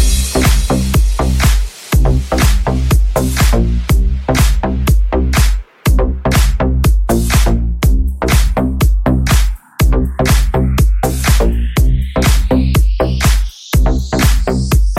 Category: Music Ringtones